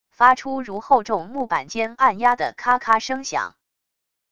发出如厚重木板间按压的咔咔声响wav音频